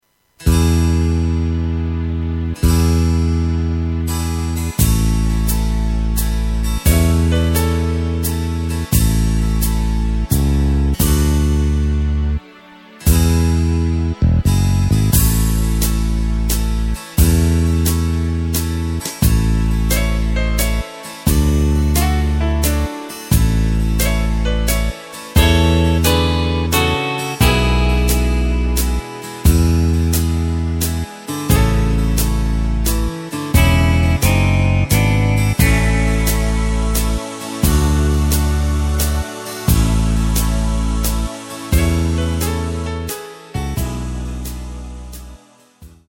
Takt:          3/4
Tempo:         96.00
Tonart:            C
Schlager aus dem Jahr 1972!
Playback mp3 mit Lyrics